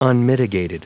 Prononciation du mot unmitigated en anglais (fichier audio)
Prononciation du mot : unmitigated